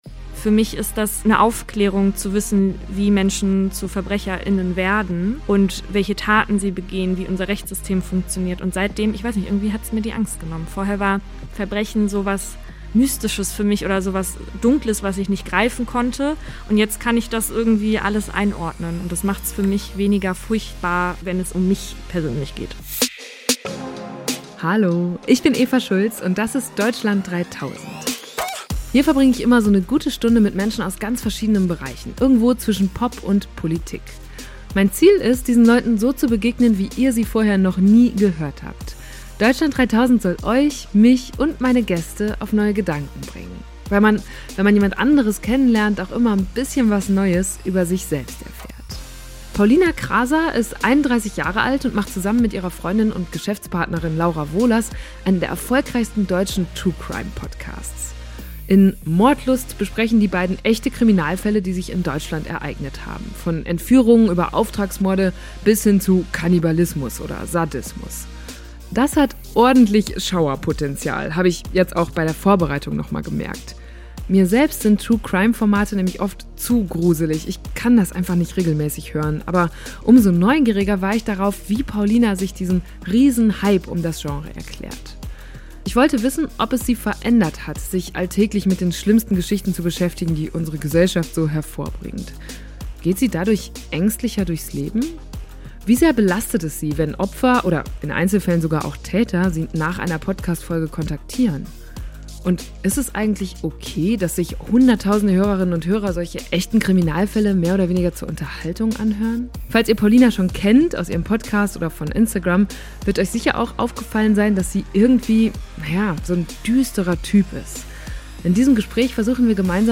In diesem Gespräch versuchen wir gemeinsam herauszufinden, woran das liegt.